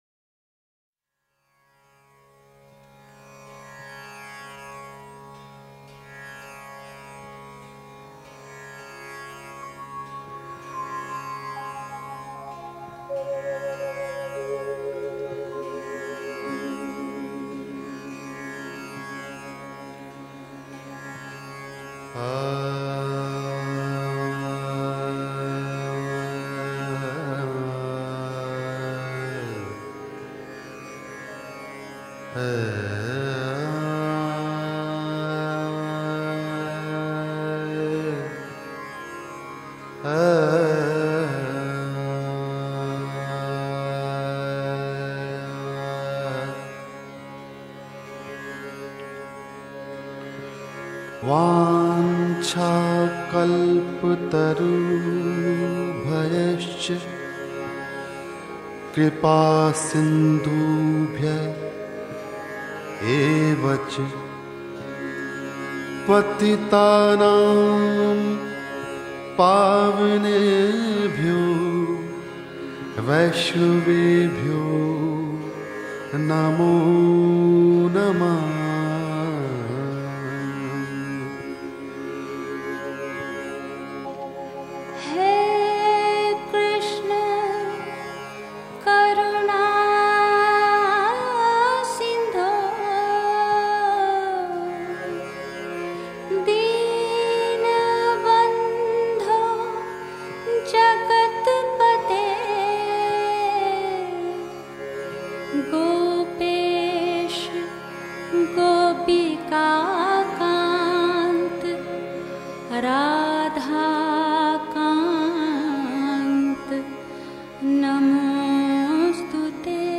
Devotional Songs
Krishna Bhajans